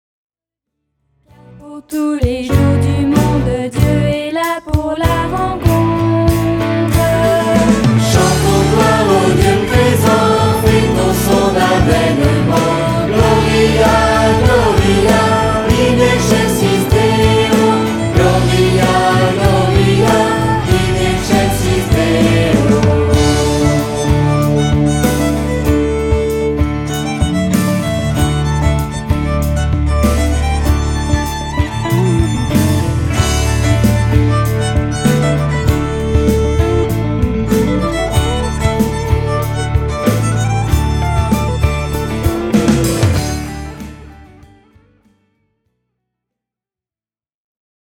Usage : Louange
MIDI 4 voix